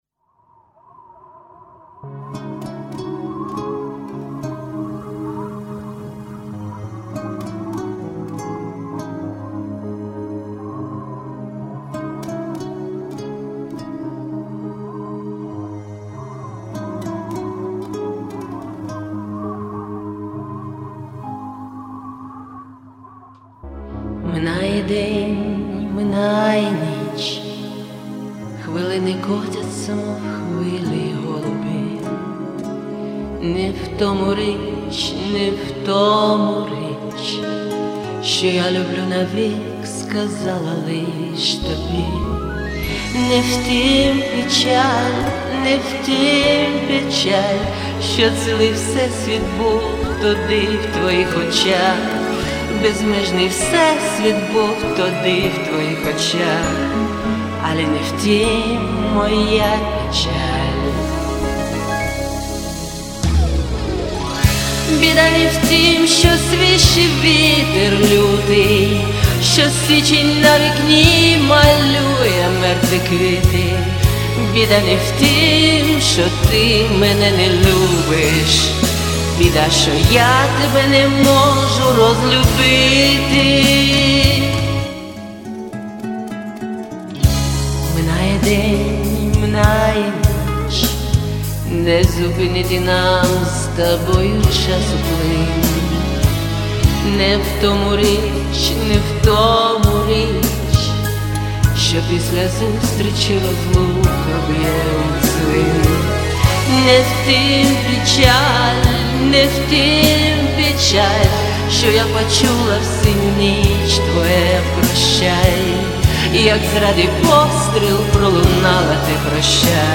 здесь кавер мой